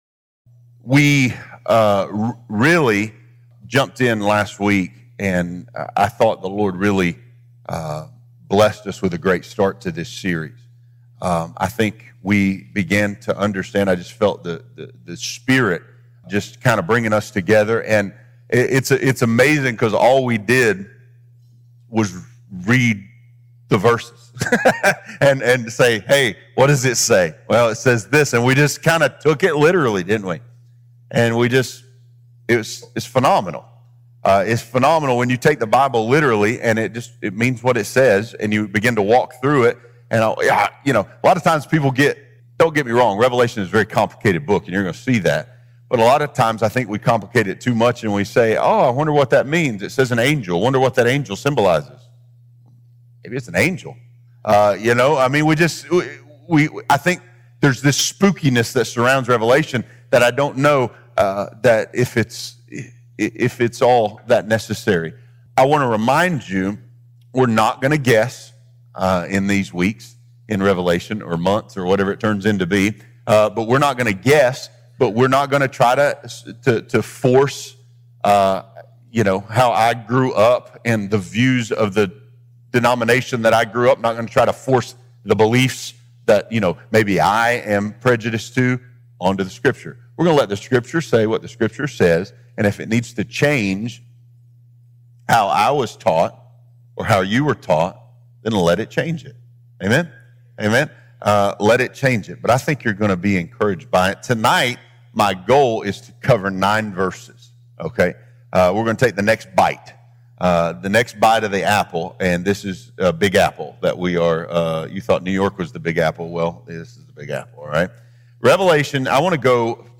Catalyst Bible Studies | Catalyst Church Hayesville